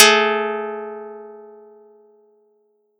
Audacity_pluck_13_14.wav